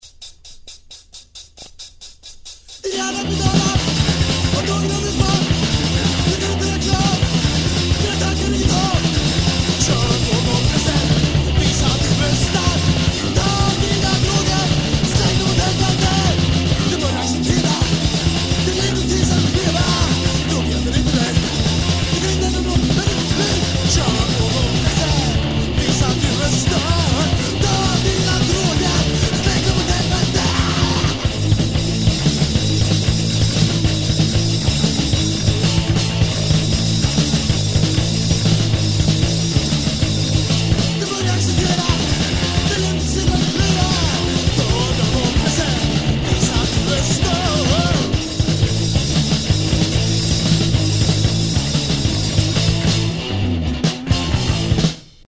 a quartet, who tried to play fast punk
Guitar, voice
Drums